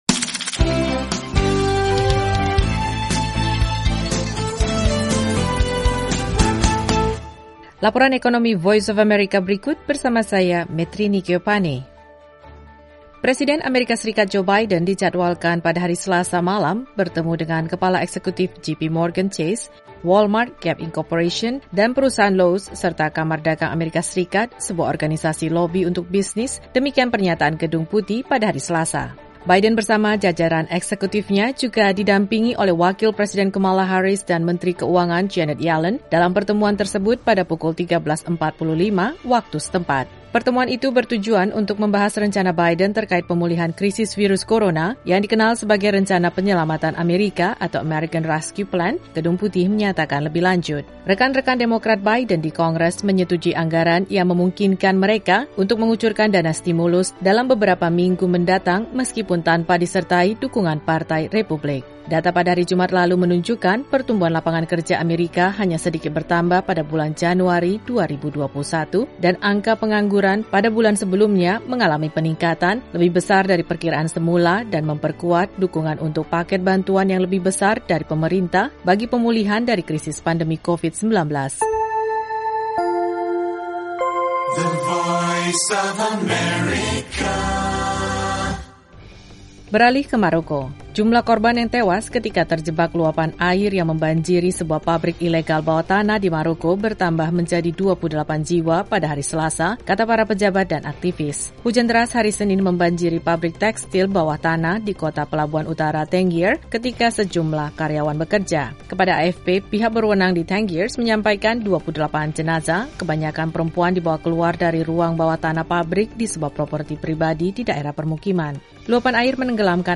Info Ekonomi